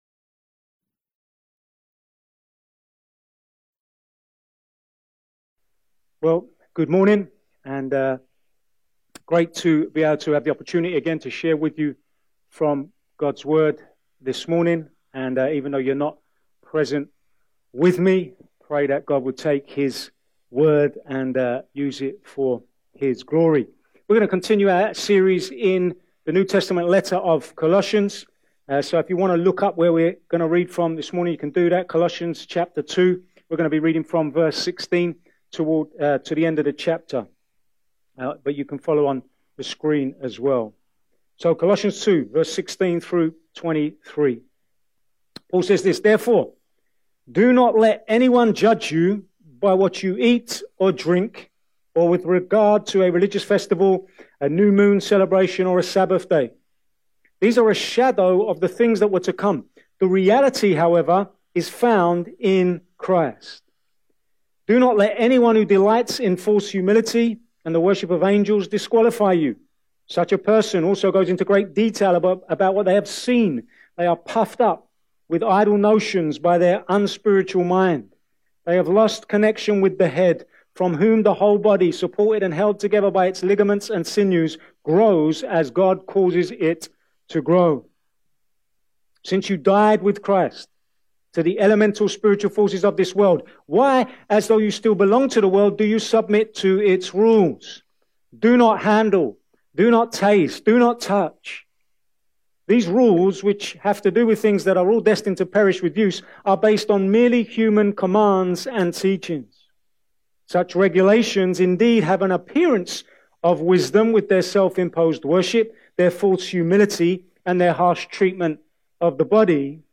A message from the series "Sunday Morning." Paul warns the Colossian believers about three dangers that would seek to divert their hope away from Christ and back onto their own works and performance. He warns the believers not to let the religious judge them, the mystics disqualify them, or the legalists enslave them, but encourages them to continue to root their confidence in Christ.